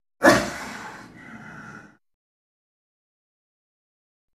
LeopardSnowBreath AT018502
Leopard, Snow Breath. Chuff, Spit Alone.